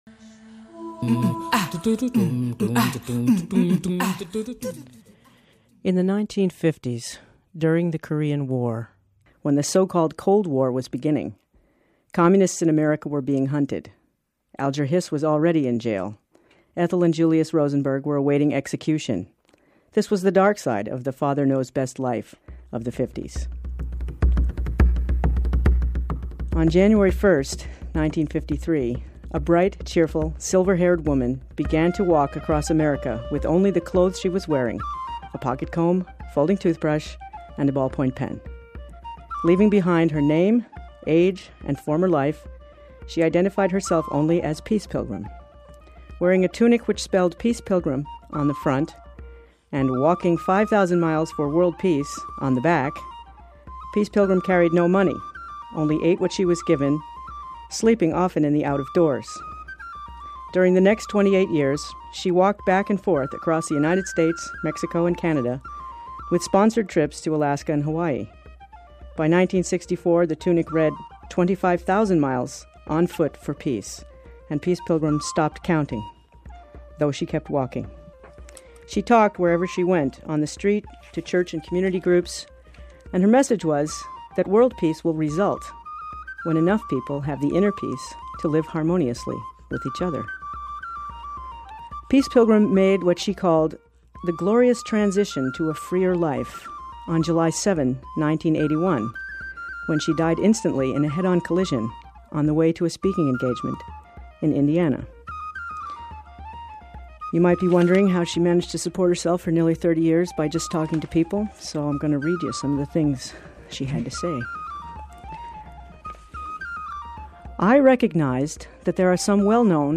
a radio piece